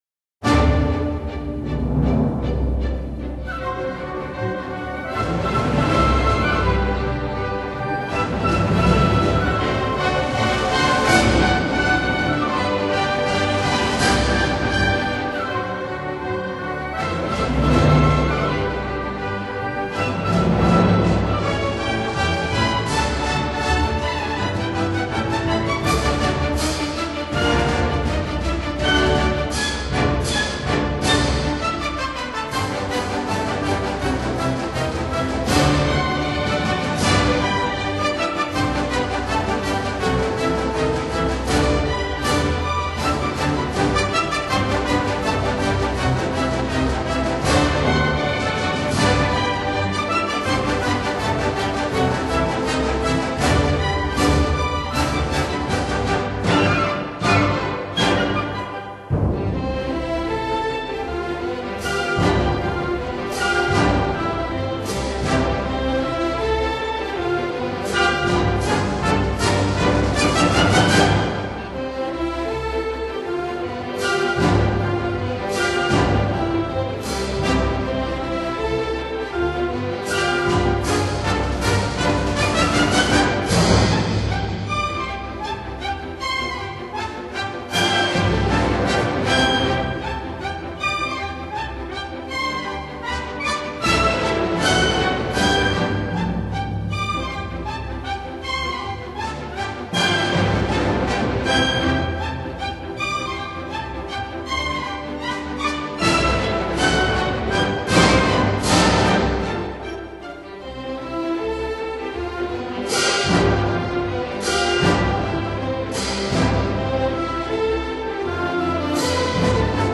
Polka schnell op.324